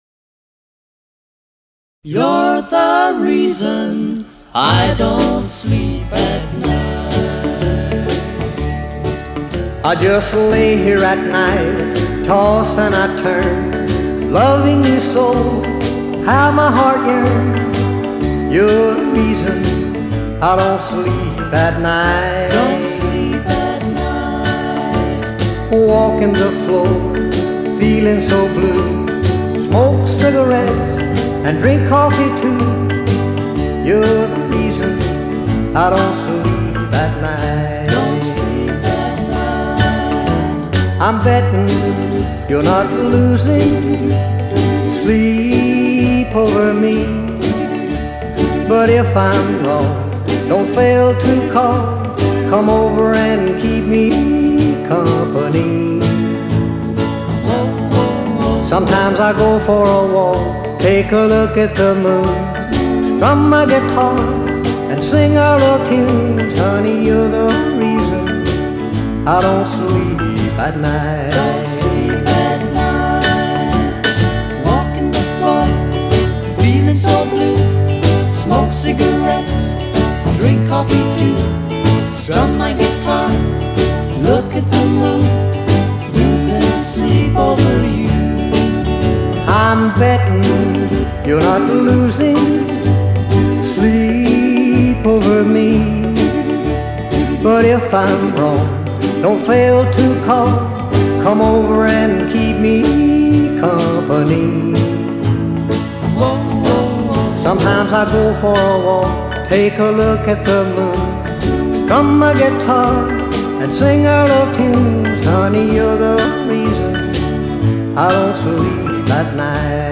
Style: Oldies 50's - 60's / Rock' n' Roll / Country